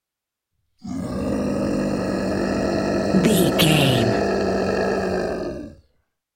Monster scream big creature
Sound Effects
scary
ominous
eerie
horror